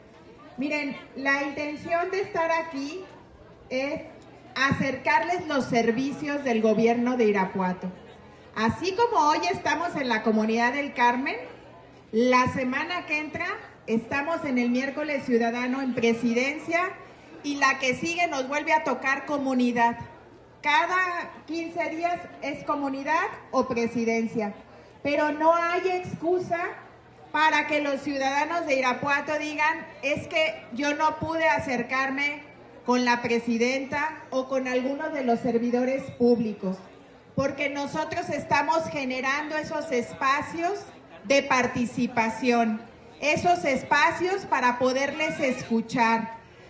AudioBoletines
Lorena Alfaro, presidenta de Irapuato